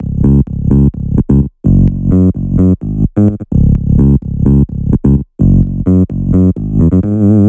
VFH2 128BPM Tron Quarter Melody 1.wav